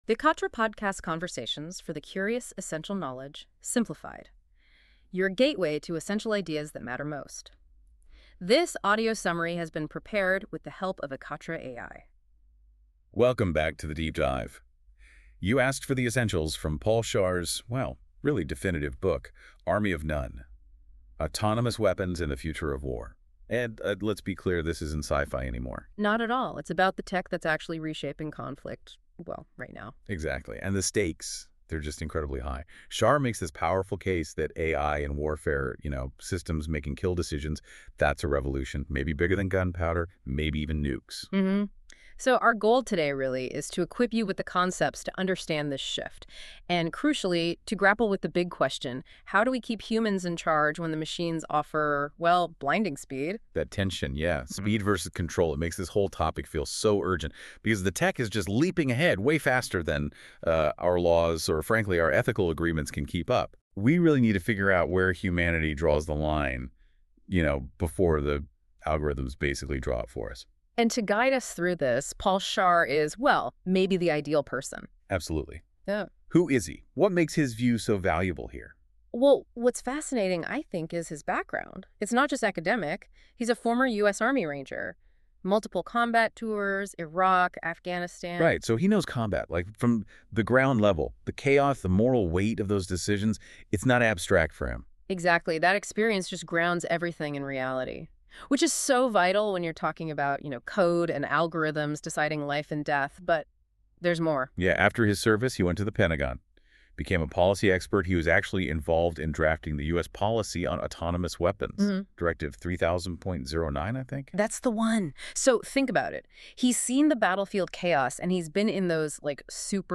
Ekatra audio summary – English